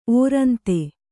♪ ōrante